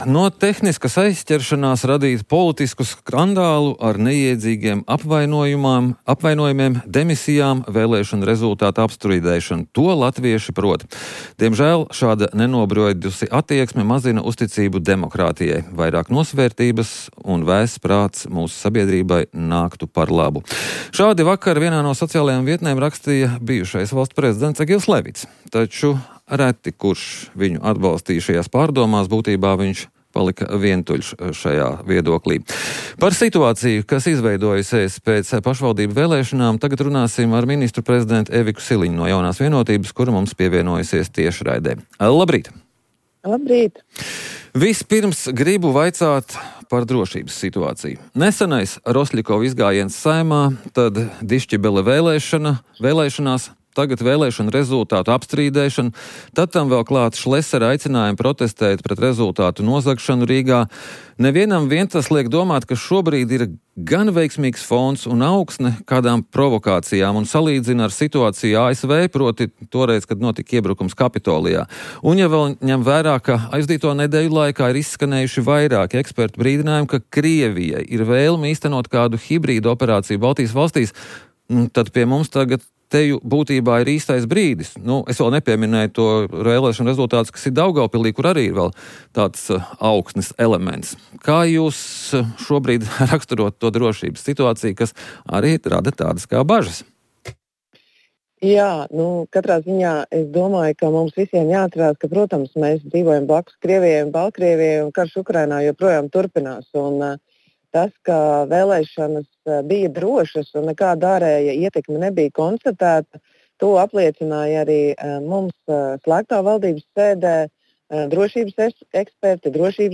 Eiropas Komisija devusi mandātu LMT līdzdalībai sešos Aizsardzības fonda projektos – Rīta intervija – Podcast